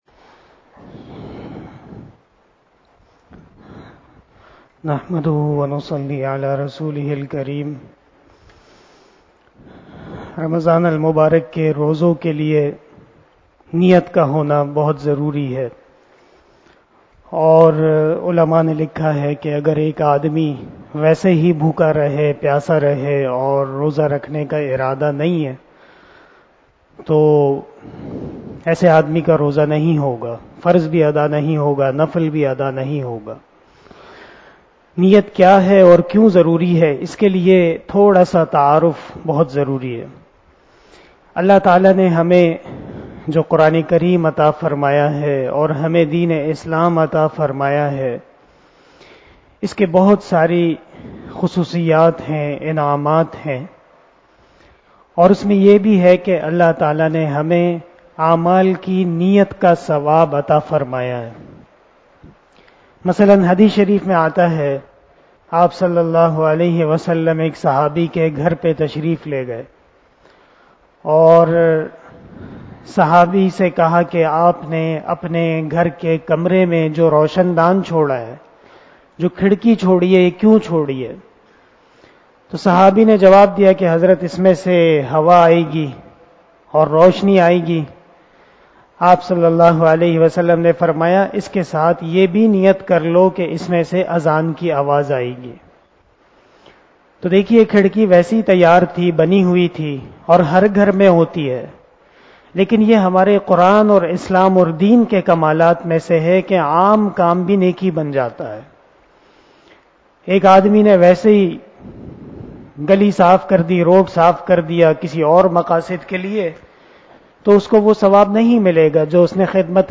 027 After Traveeh Namaz Bayan 04 April 2022 ( 03 Ramadan 1443HJ) Monday
بیان بعد نماز تراویح